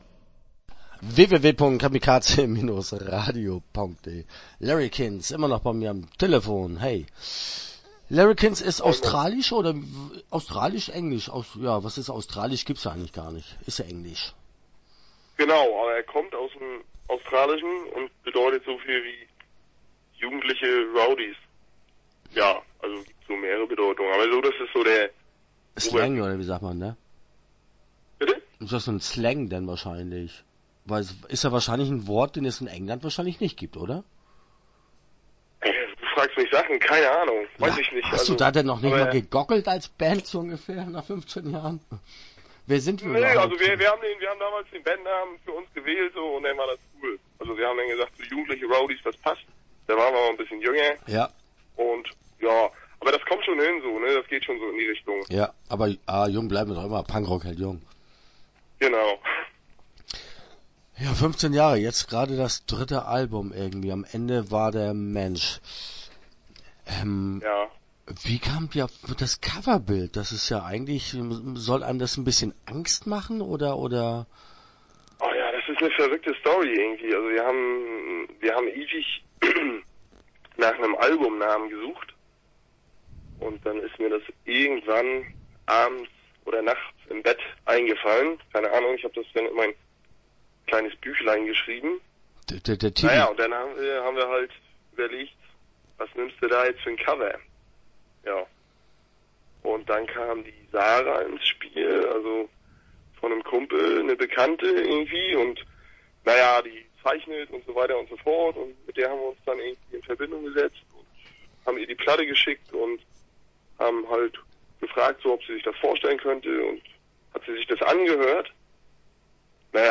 Larrikins - Interview Teil 1 (9:31)